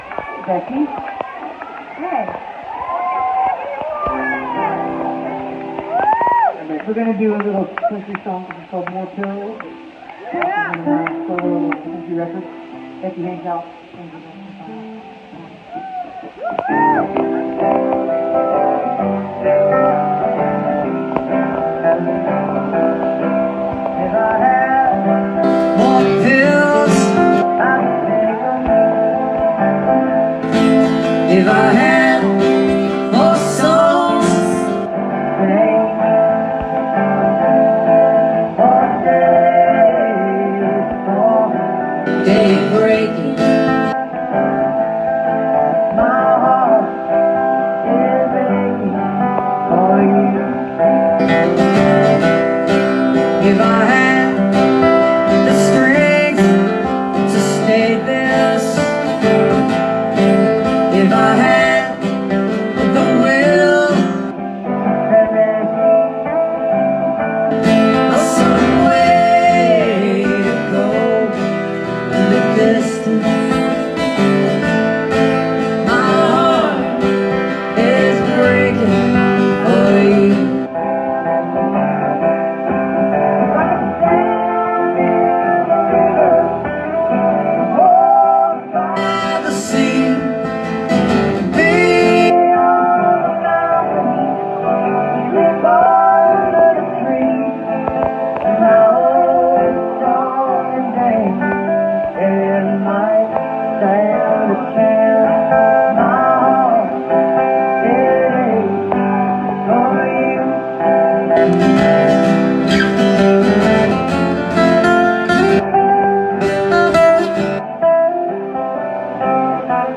(captured from a facebook live stream)